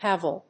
/ˈhævʌl(米国英語)/